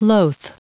Prononciation du mot loath en anglais (fichier audio)
Prononciation du mot : loath